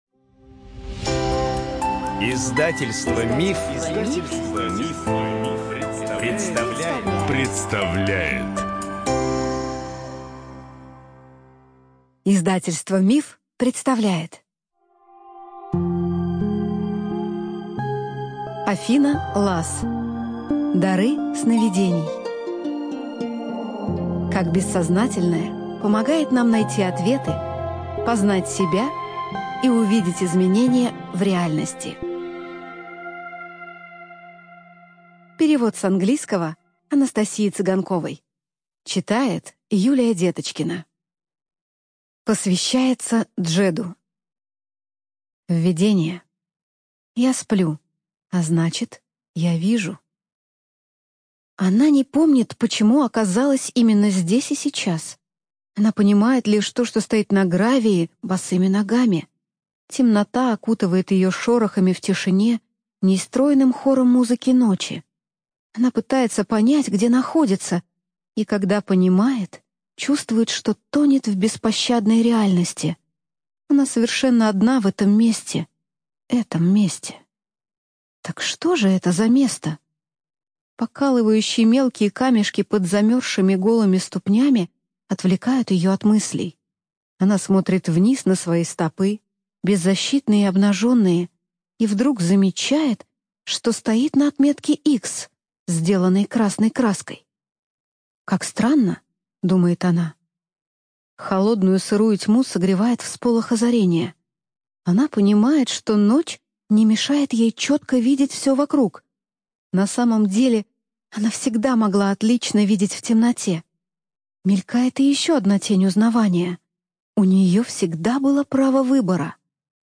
Студия звукозаписиМанн, Иванов и Фербер (МИФ)